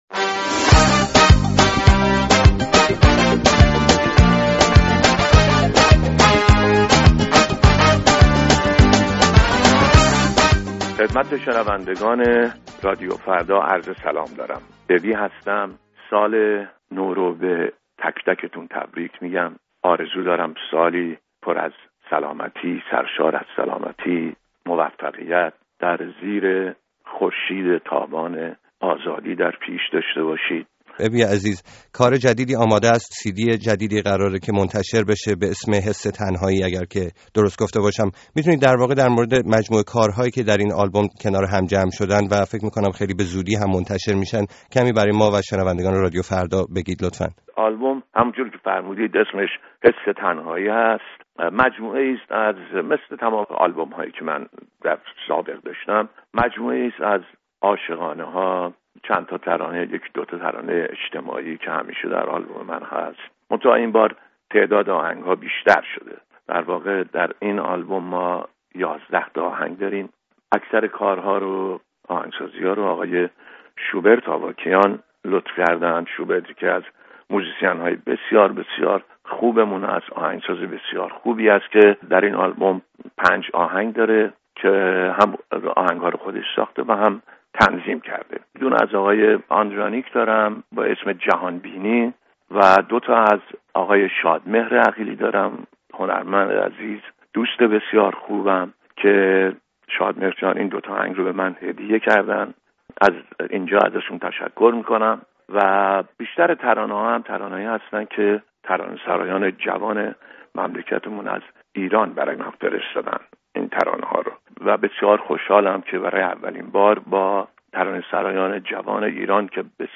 گفت‌وگوی نوروزی با ابراهیم حامدی «ابی»